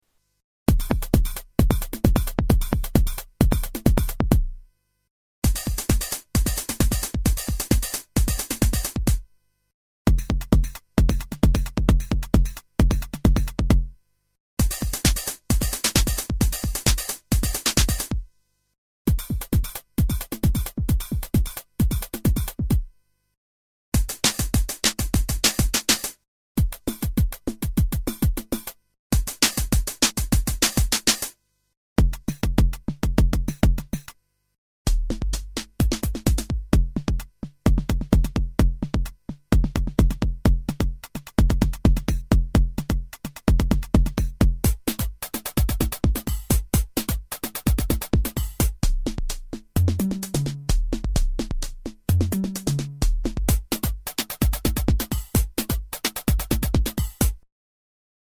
XBase-09 demo 2
Category: Sound FX   Right: Personal
Tags: Sound Effects JoMoX Sounds JoMoX XBase AirBase